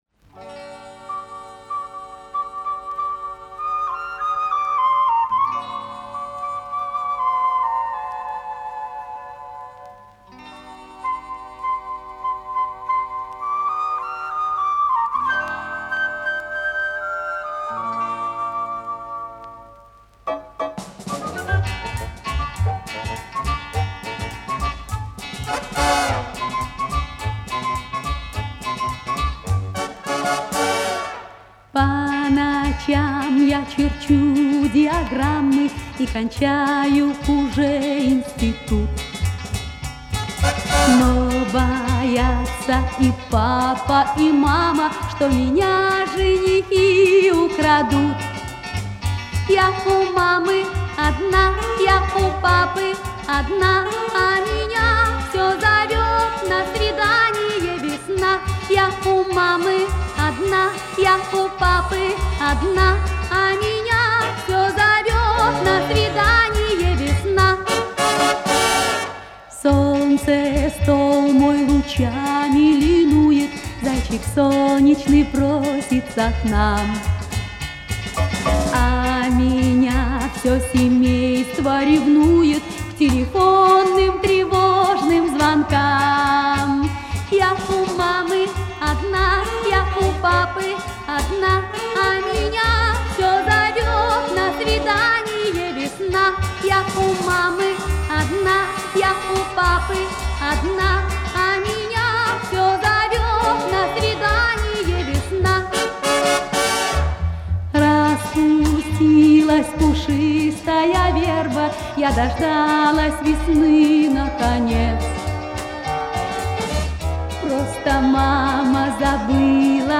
Взято с пластинки grand тех лет.